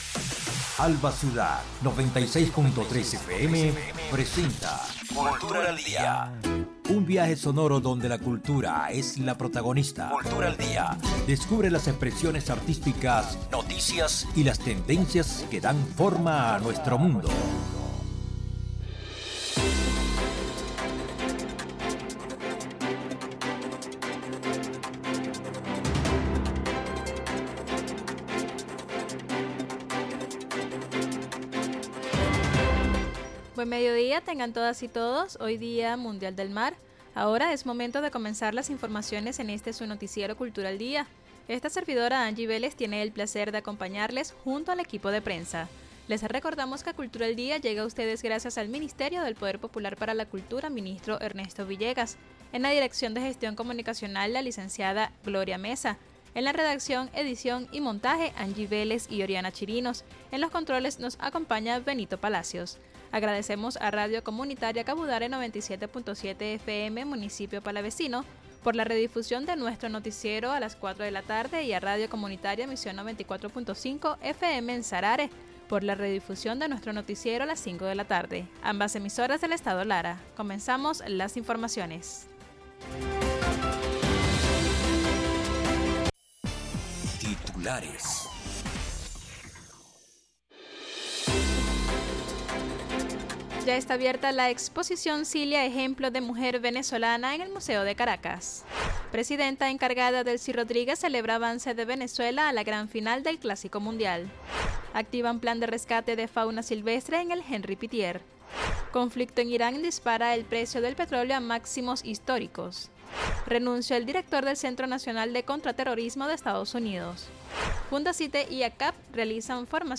Noticiero de Alba Ciudad. Recorrido por las noticias más resaltantes del acontecer nacional e internacional, dando prioridad al ámbito cultural.